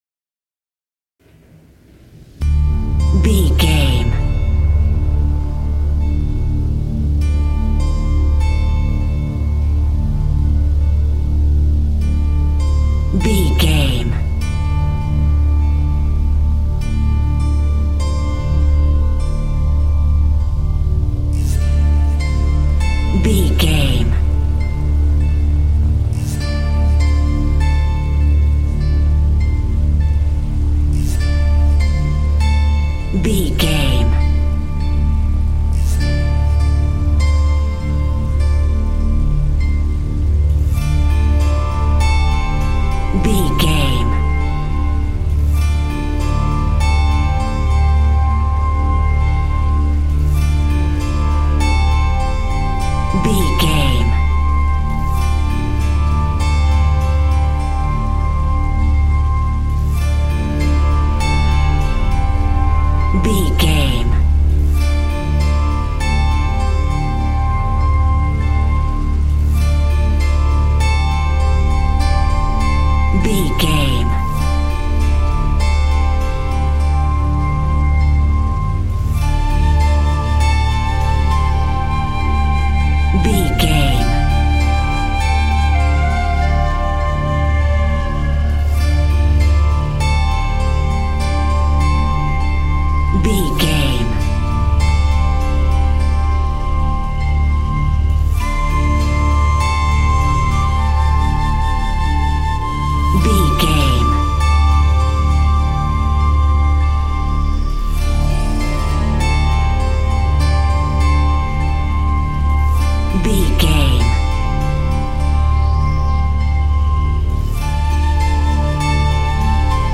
Ionian/Major
Slow
light
relaxed
tranquil
synthesiser
drum machine